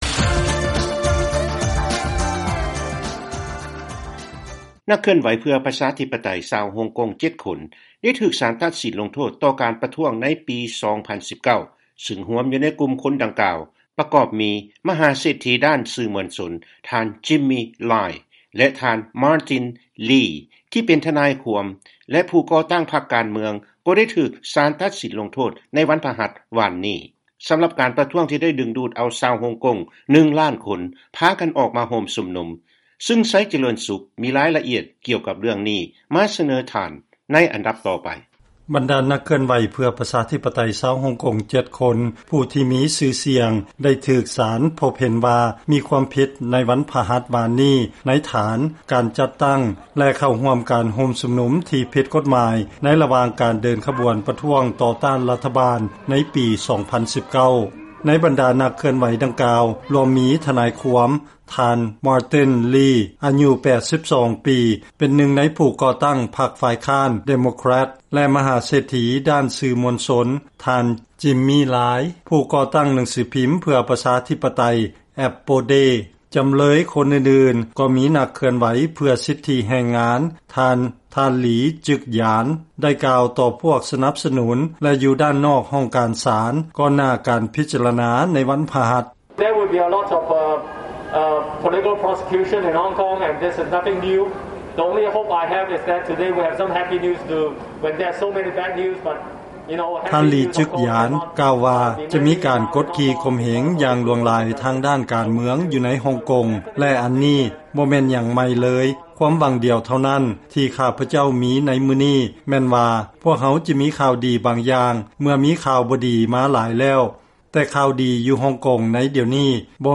ຟັງລາຍງານ ນັກເຄື່ອນໄຫວເພື່ອປະຊາທິປະໄຕຂອງຮົງກົງ 7 ຄົນ ໄດ້ຖືກສານຕັດສິນ ວ່າຜິດ ໃນຖານການປະທ້ວງເມື່ອປີ 2019